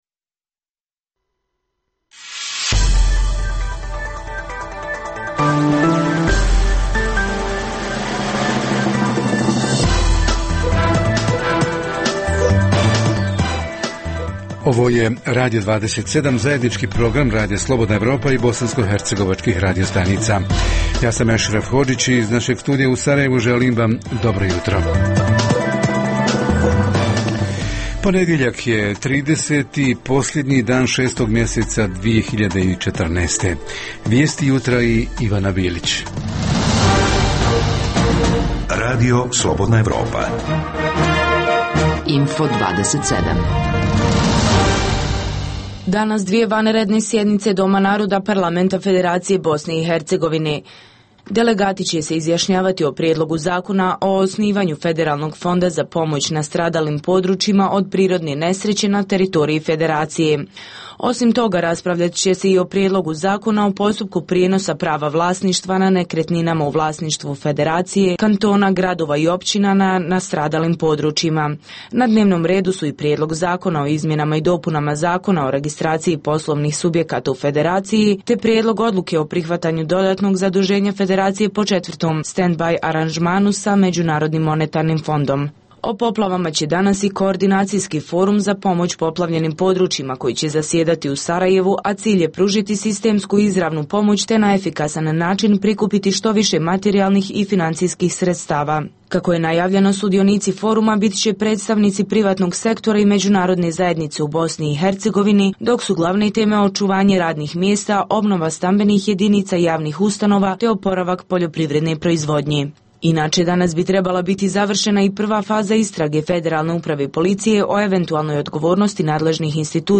Jutarnji program za BiH koji se emituje uživo. Ponedjeljkom govorimo o najaktuelnijim i najzanimljivijim događajima proteklog vikenda.
Redovni sadržaji jutarnjeg programa za BiH su i vijesti i muzika.